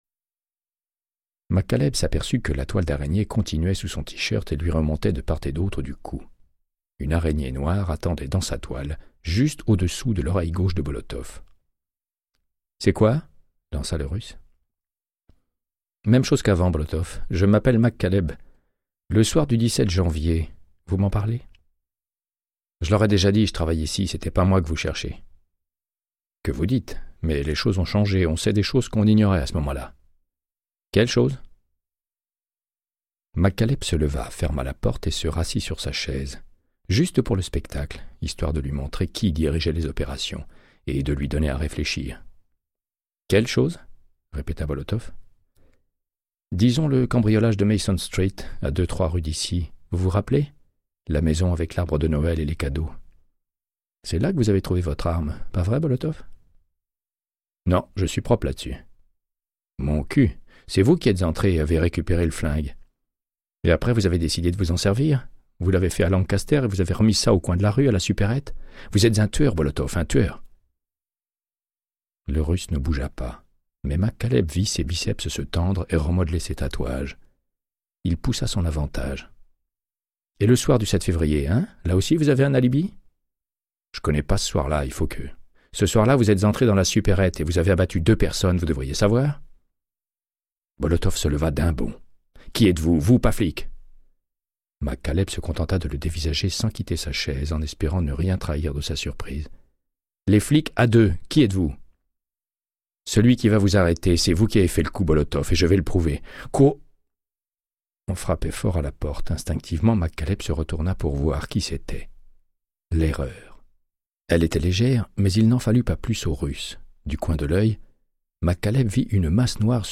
Audiobook = Créance de sang, de Michael Connellly - 61